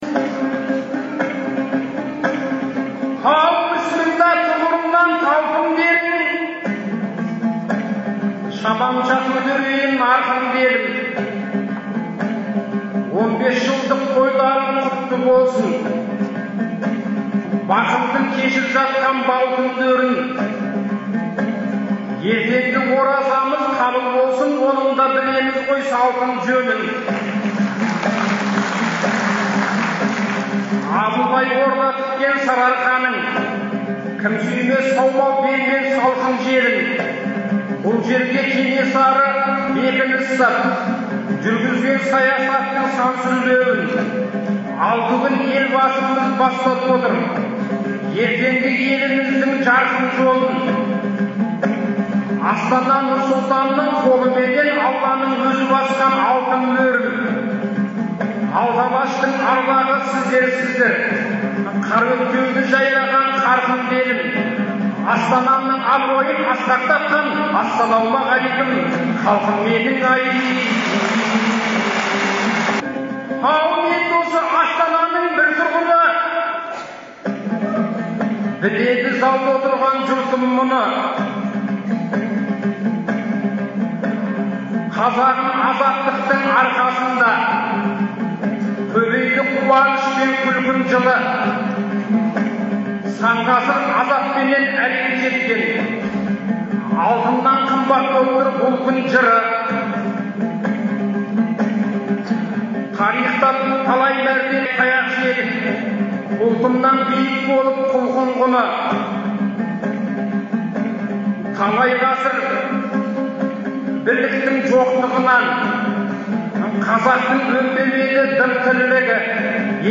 Шілденің 8-9 күндері Астанада қала күніне орай «Ел, Елбасы, Астана» деген атпен ақындар айтысы өтті.